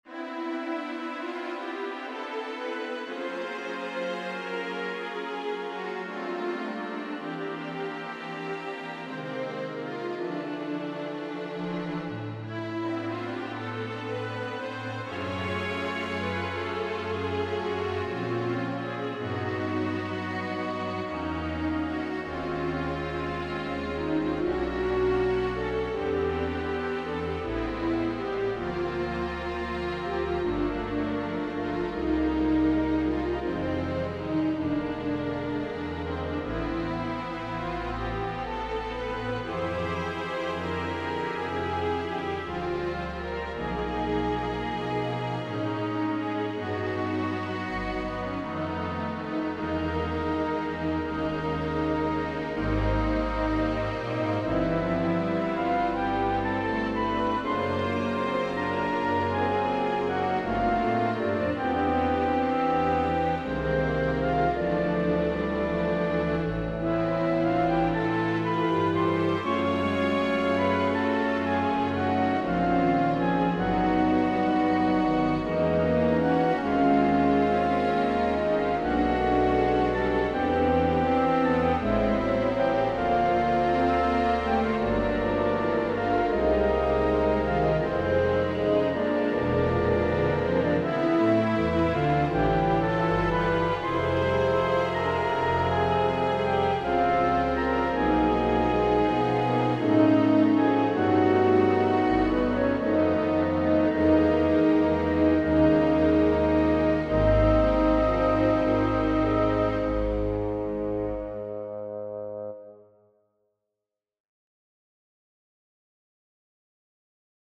1st violins, 2 violins, violas, cellos, basses .